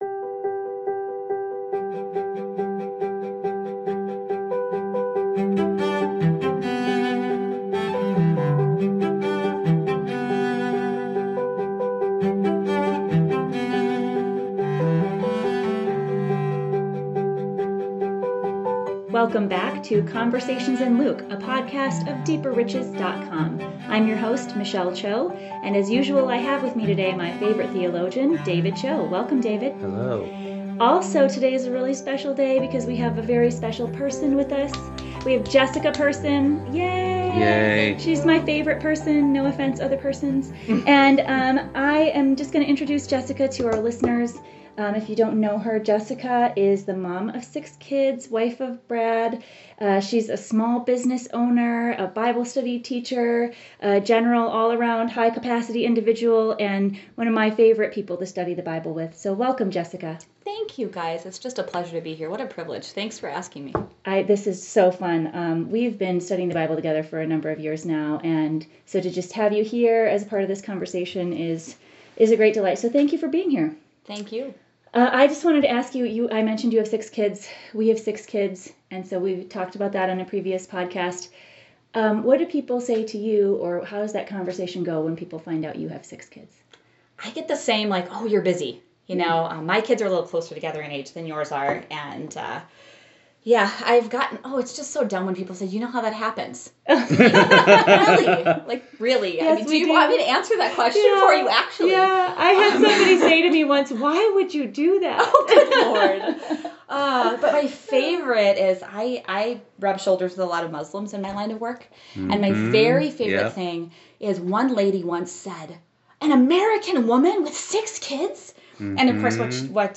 Read Luke 7-8, then listen to the conversation!